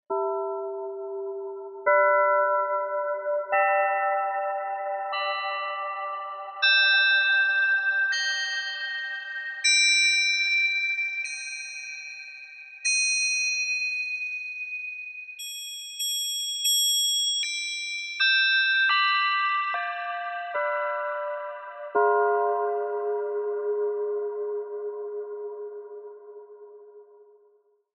e. Expressive Bell
Zorg ervoor dat de bell expressiever wordt met door ook de velocity en duratie te kunnen aanpassen.